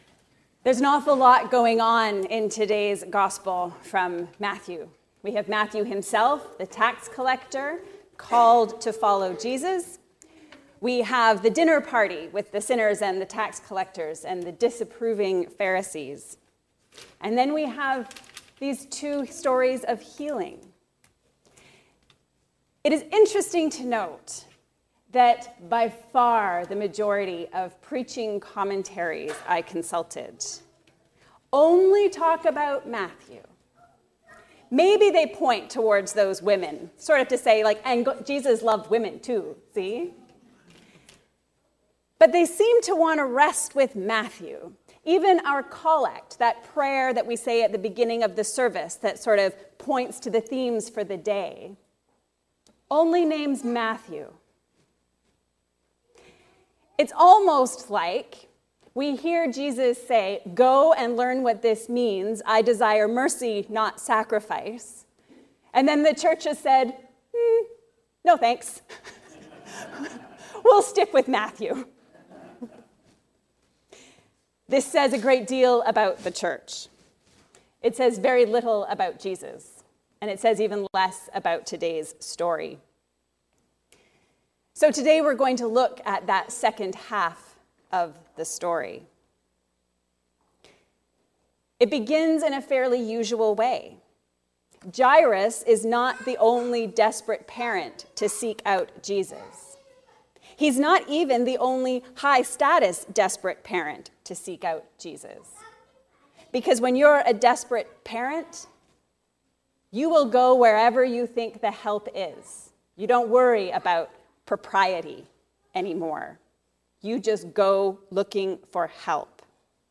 A Sermon for the Second Sunday After Pentecost